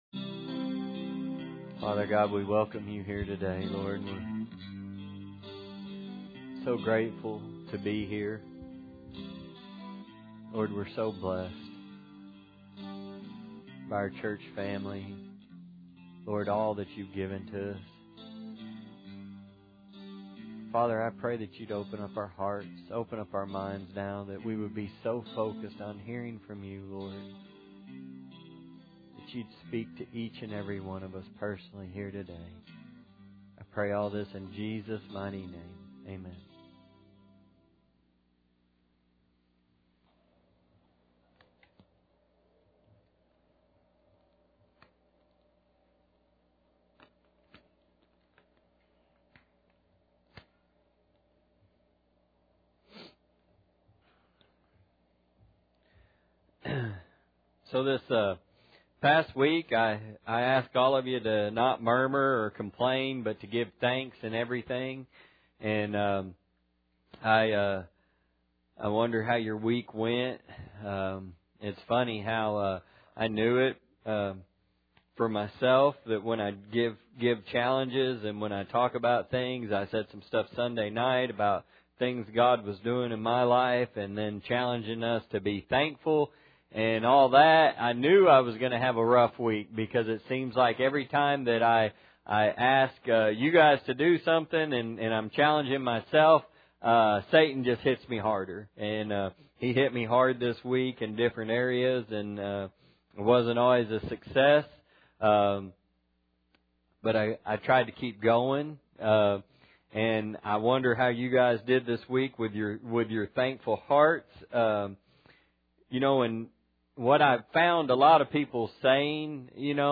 1 Samuel 16:7 Service Type: Sunday Morning Bible Text